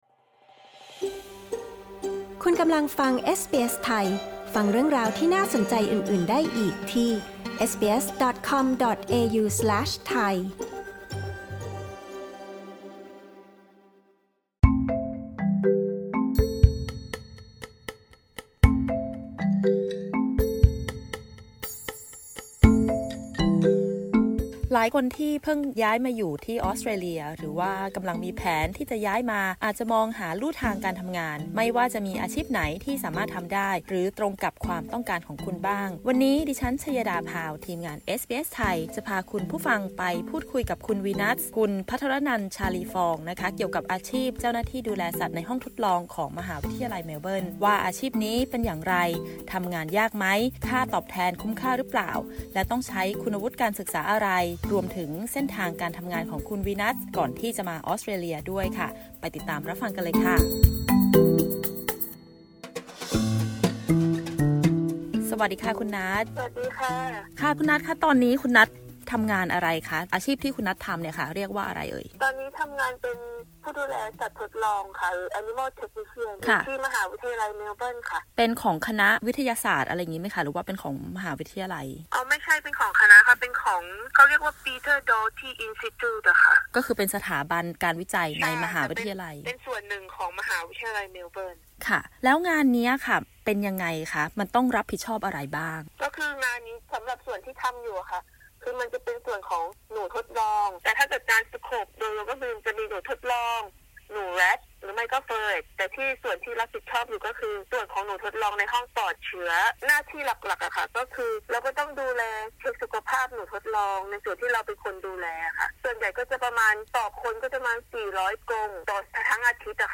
ประเด็นสำคัญในการสัมภาษณ์